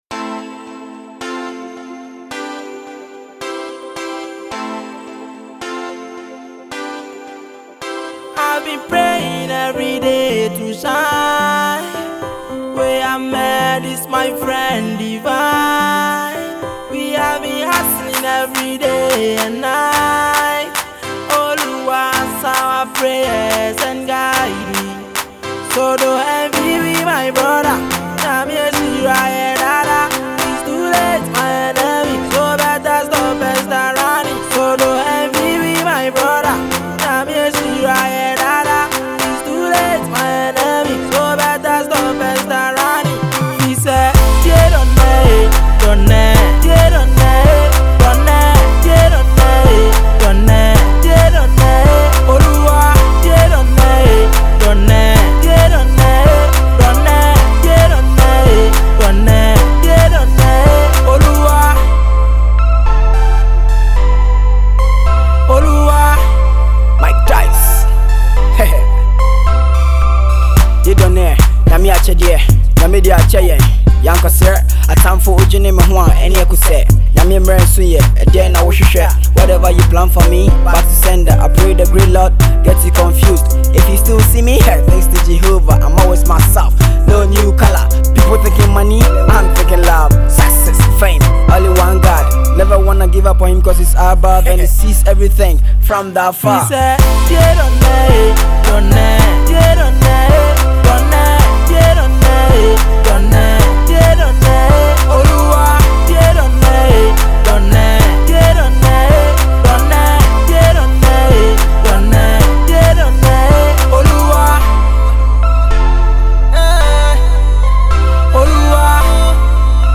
Urban Gospel
Dangme rapper